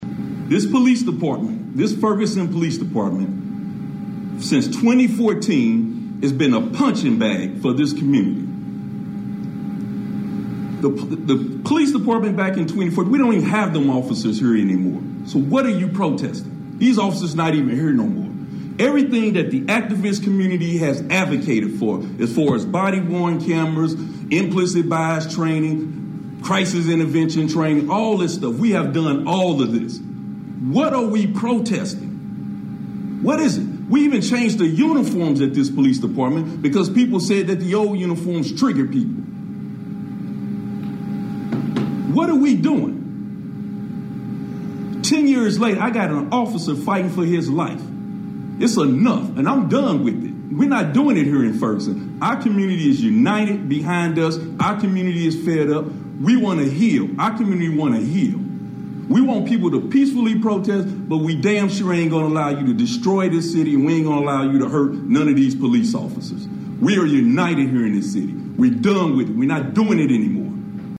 Saturday afternoon, Chief Doyle gave a press conference.
ferguson-police-chief-troy-doyle.mp3